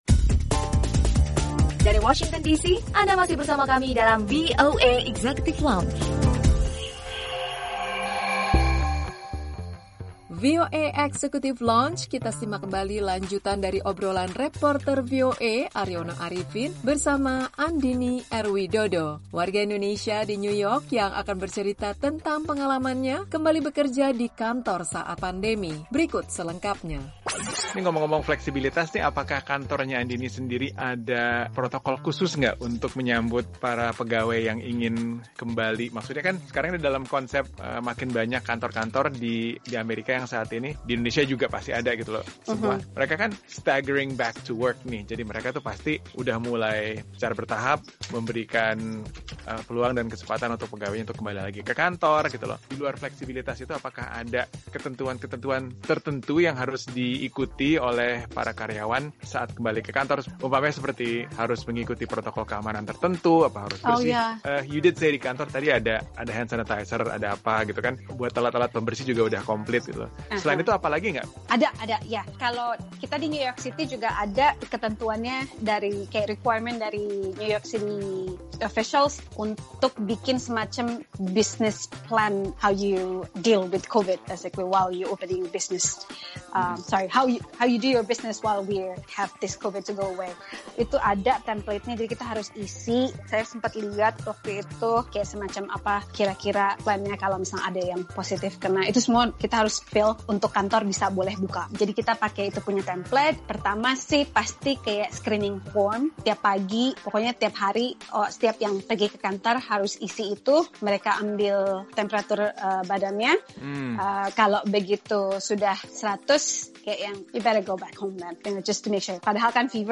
Lanjutan dari obrolan